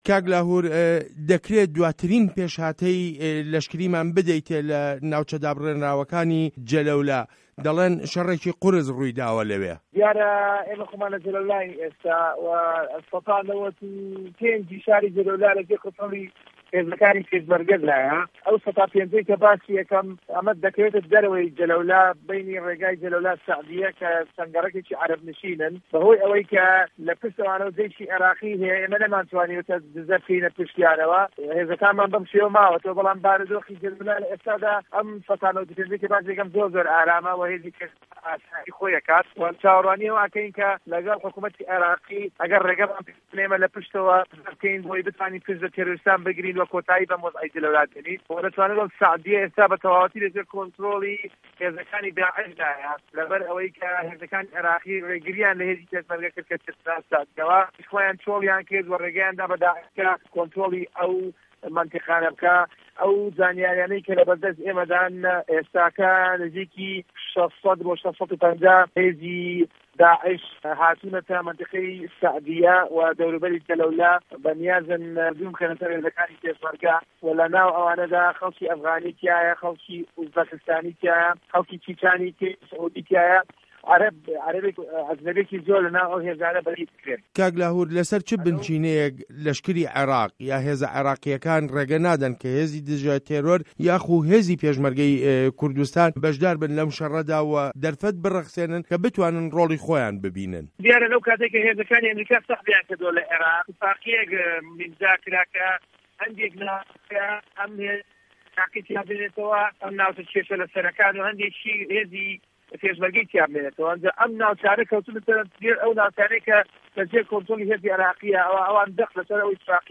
وتووێژ له‌گه‌ڵ لاهور جه‌نگی